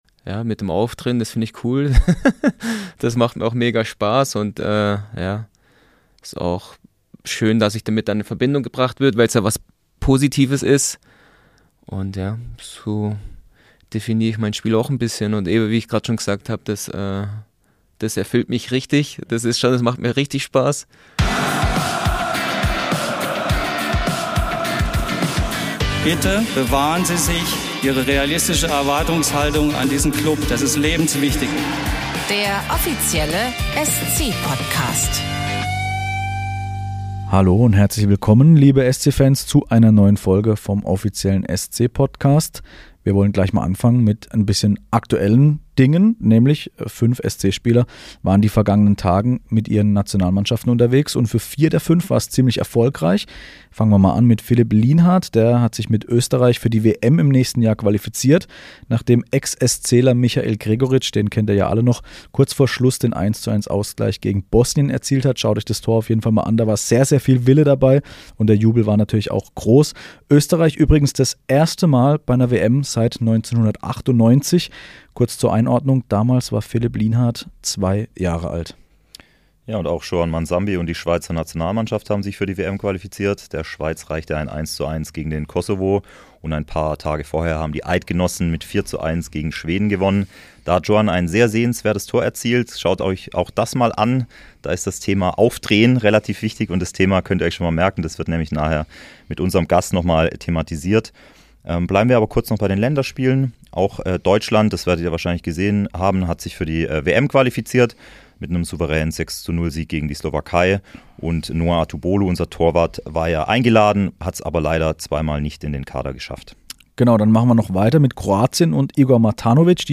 Beschreibung vor 4 Monaten Im Podcast-Interview spricht Chicco Höfler über das Aufdrehen und Einköpfen – aber auch über das Familienleben mit sieben Kindern und eine mögliche Trainerkarriere. 369 Pflichtspiele hat der 35-Jährige für den SC bereits absolviert, damit steht er auf Rang drei der internen Rekordspieler-Liste.